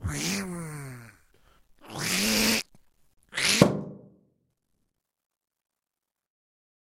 je duckexplode
描述：鸭子呱呱叫，然后爆炸了。由处理过的人声和气球爆裂产生。
标签： 爆炸 嘎嘎
声道立体声